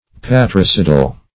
Patricidal \Pat*ri"ci`dal\, a. Of or pertaining to patricide; parricidal.